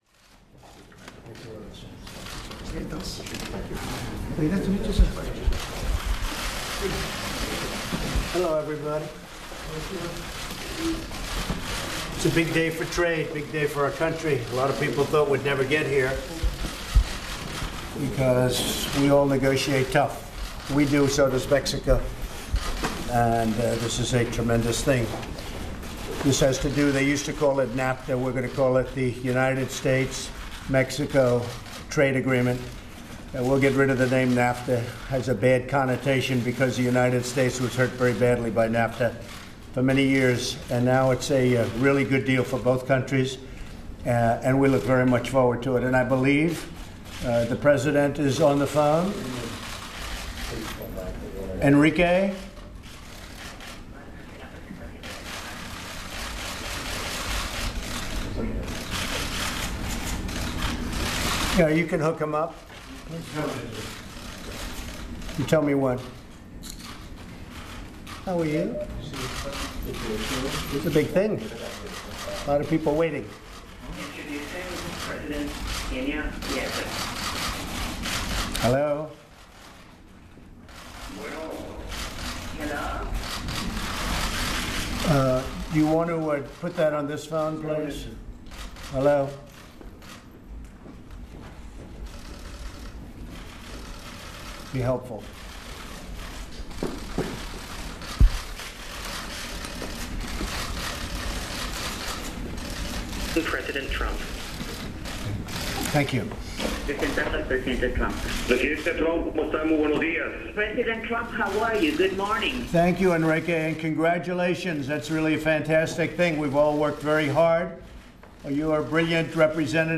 Trump also says that Canada is not included in the agreement, but that negotiations with that country will continue. Mexican President Enrique Peña Nieto joins the announcement by speakerphone. Held in the Oval Office.